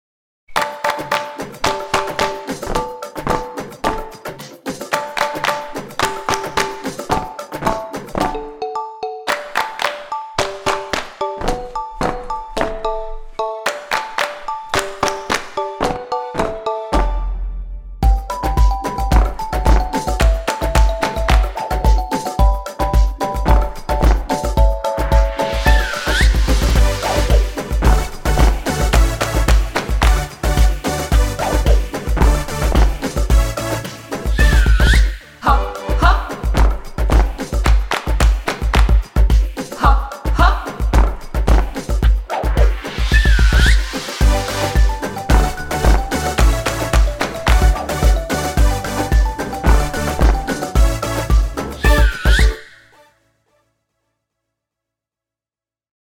ukaz-co-vies-podklad.mp3